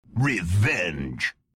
halo reach revenge voice
halo-reach-revenge-voice.mp3